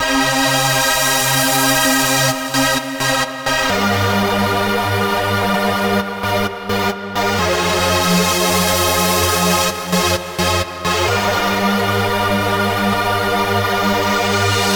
chords.wav